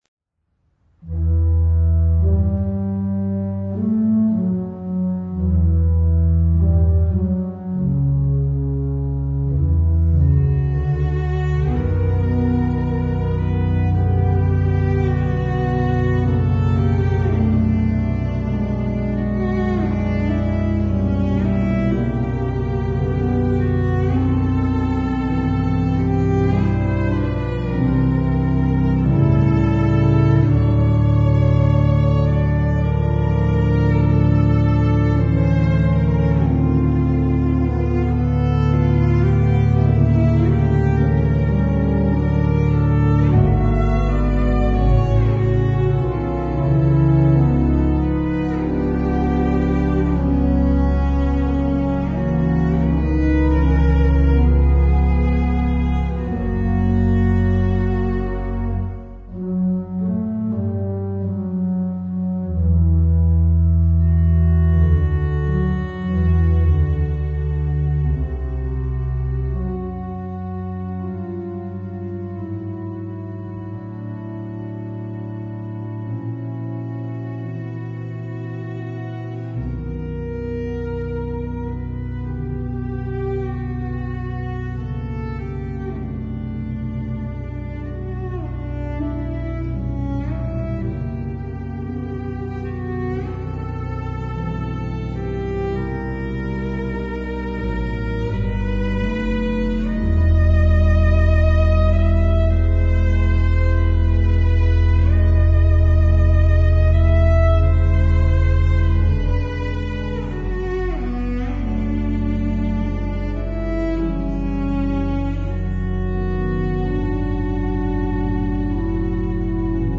all live, and all unedited.
Cello and Organ